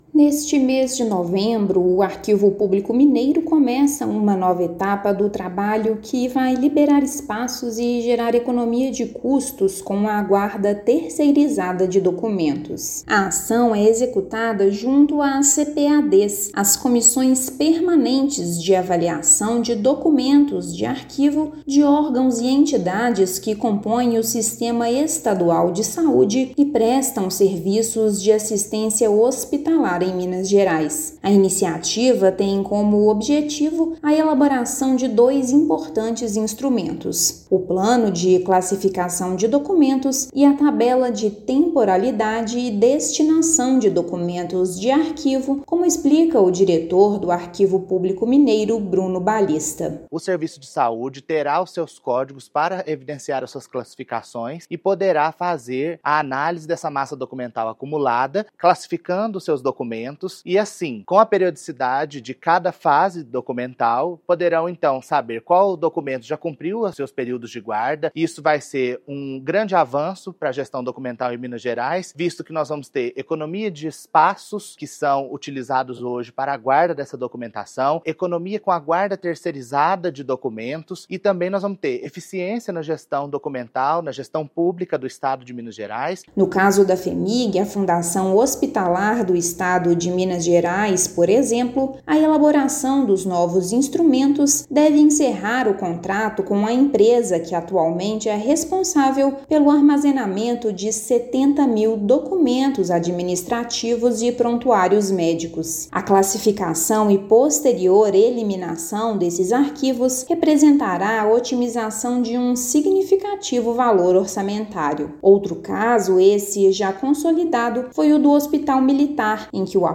[RÁDIO] Arquivo Público Mineiro aprimora armazenamento de documentos para gerar economia aos serviços de saúde
Ação é fruto do acordo de cooperação técnica entre Secult-MG com entidades do sistema estadual de saúde e gestoras de hospitais em Minas. Ouça matéria de rádio.